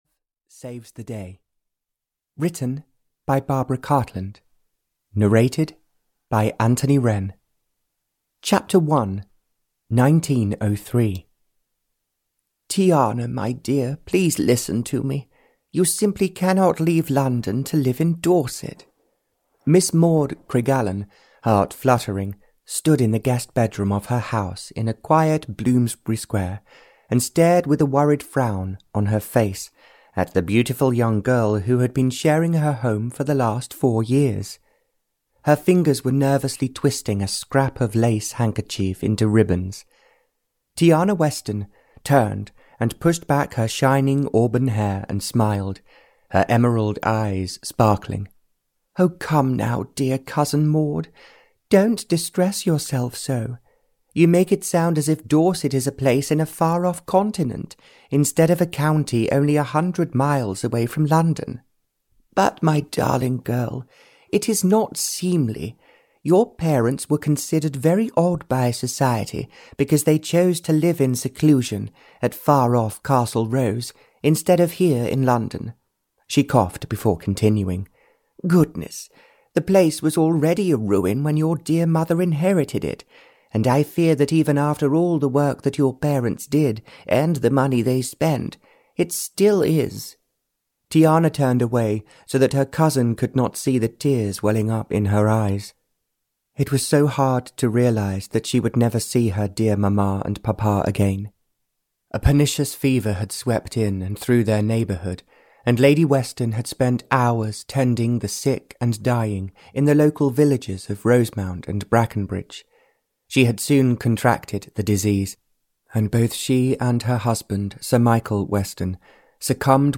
Love Saves the Day (Barbara Cartland's Pink Collection 148) (EN) audiokniha
Ukázka z knihy